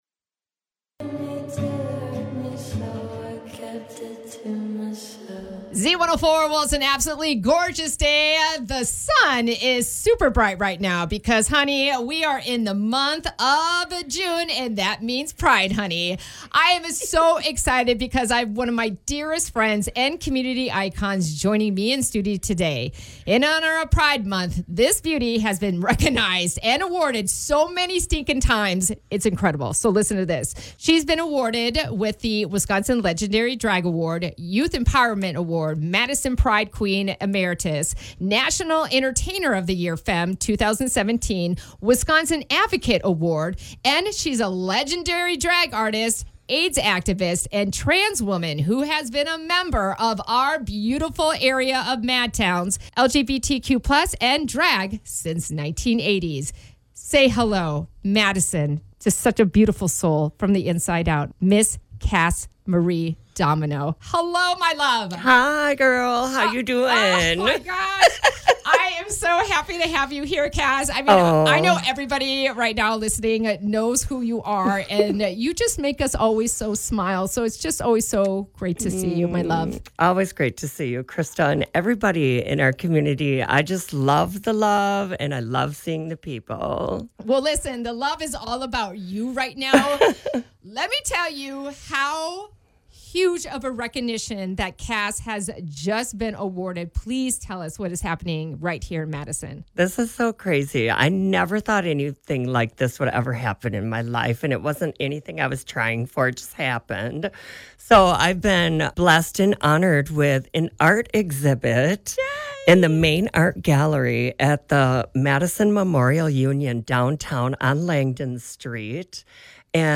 Z104 interview